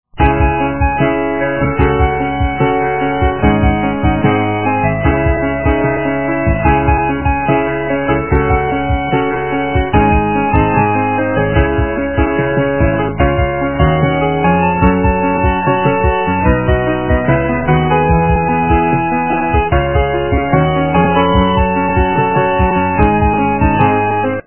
русская эстрада
качество понижено и присутствуют гудки